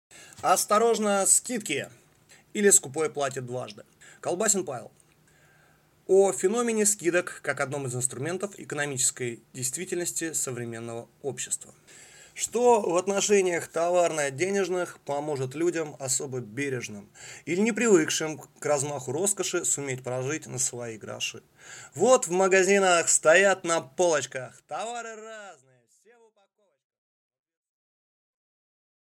Аудиокнига Осторожно скидки или скупой платит дважды | Библиотека аудиокниг